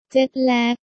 タイ語で時差ボケは「เจ็ทแลค（ジェト レーク）」などです。